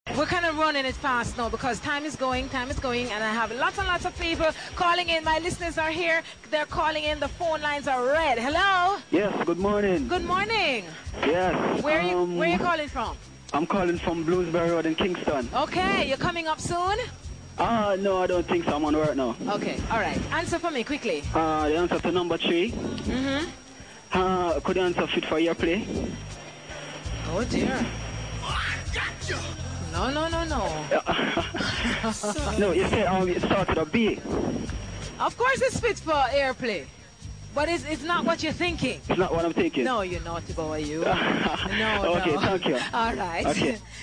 Jamaican English
In pronunciation, Jamaican English (on various levels) is syllable-timed, non-rhotic, has simplified consonant clusters and diphthongisation of mid front vowels, e.g. [fiəs] for face .
Jamaica_Acrolectal.wav